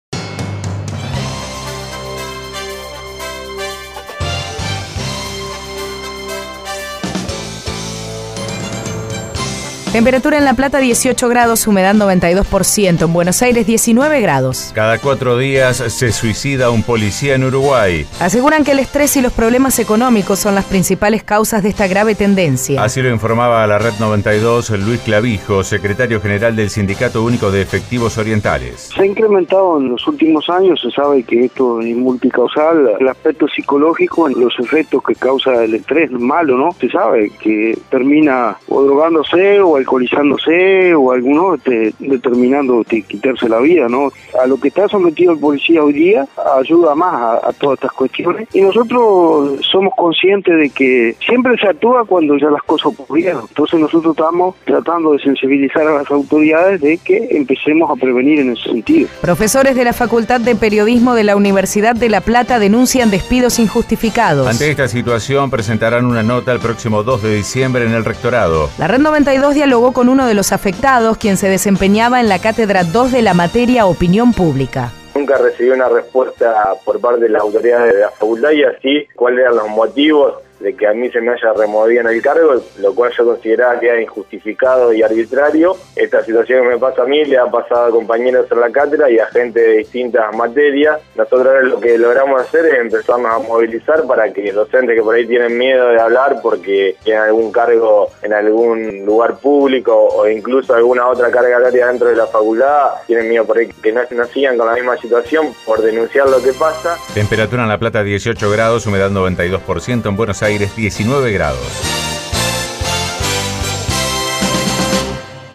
Nota de radio Red 92 de La Plata (Argentina)